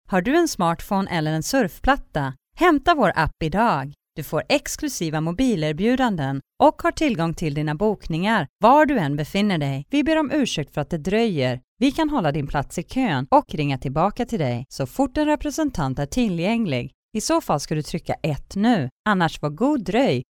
Swedish female voice over